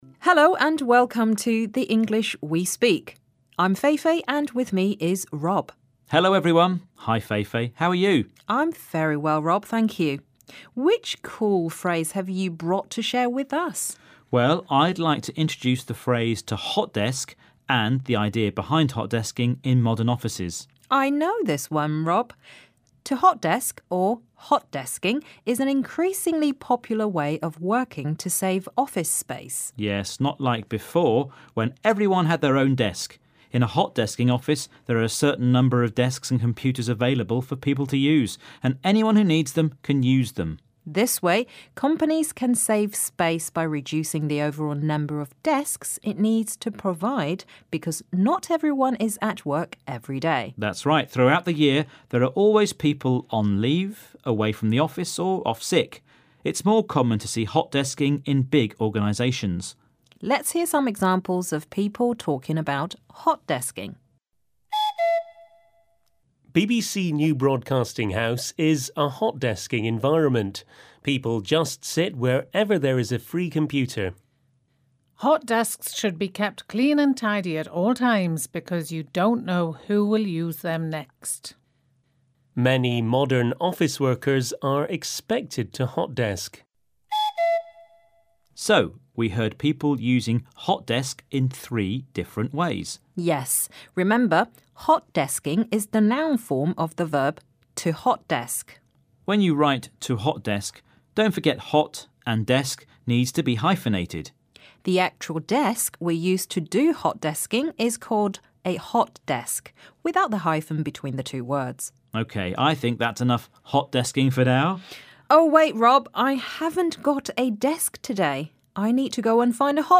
What does the phrase 'hot desk' mean? And why do big organisations choose to hot-desk? Listen to the dialogue.